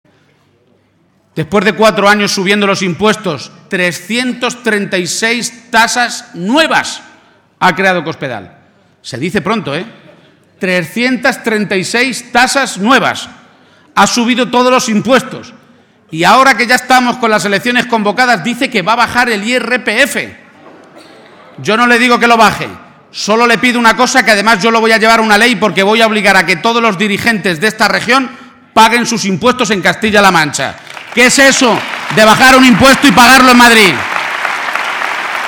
Audio Page-acto Albacete 3